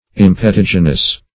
\Im`pe*tig"i*nous\